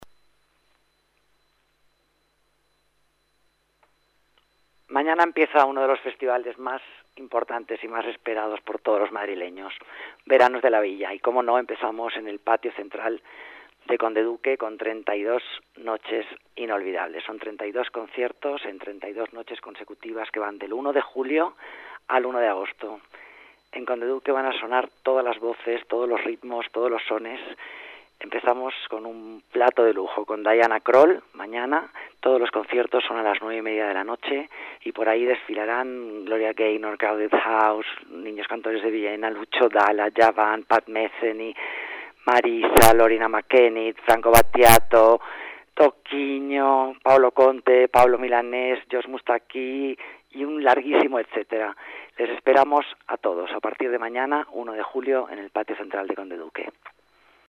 Nueva ventana:Declaraciones de la delegada de las Artes, Alica Moreno